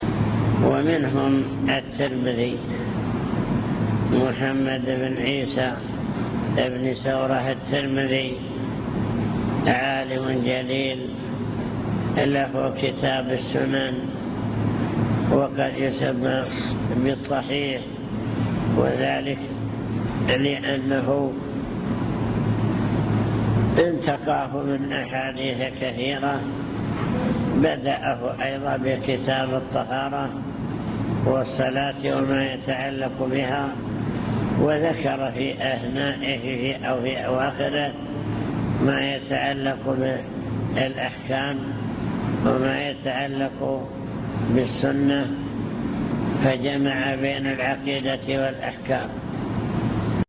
المكتبة الصوتية  تسجيلات - محاضرات ودروس  محاضرات بعنوان: عناية السلف بالحديث الشريف دور أصحاب الكتب الستة في حفظ الحديث